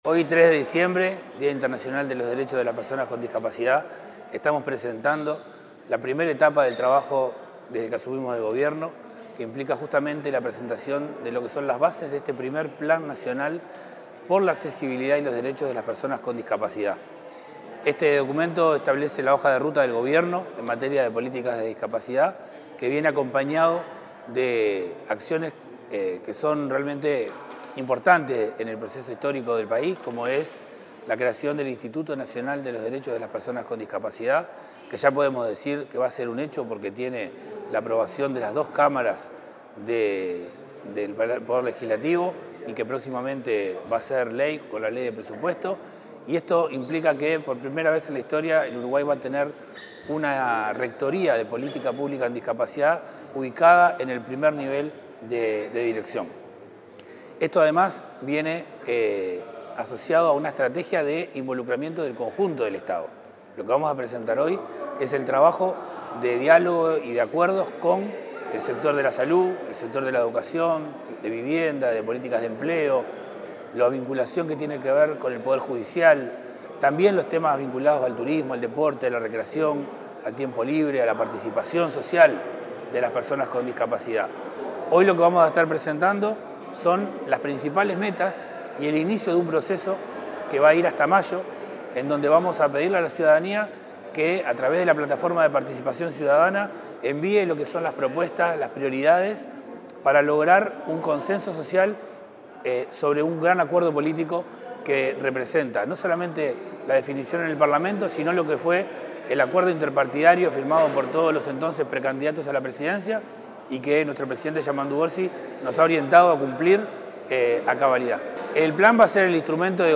Declaraciones del director de Discapacidad, Federico Lezama